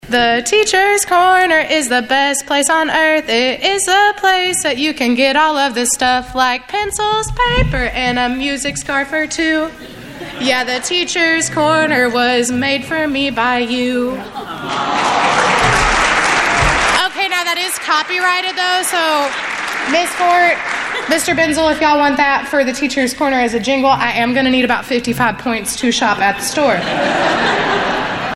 Foundation Luncheon Showcases Recent Success Stories
Naturally, she wrote a song about it.